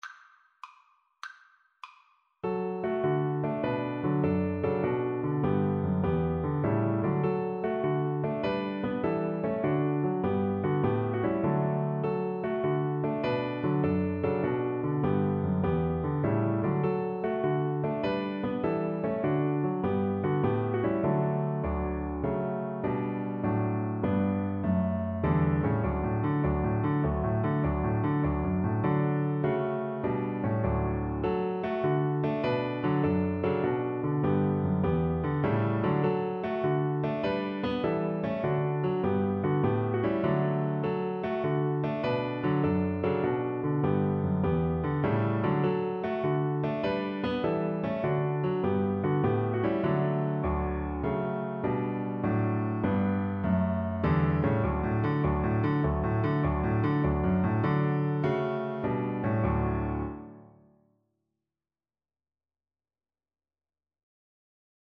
Viola
Traditional Music of unknown author.
D major (Sounding Pitch) (View more D major Music for Viola )
~ = 150 A1
6/8 (View more 6/8 Music)
Traditional (View more Traditional Viola Music)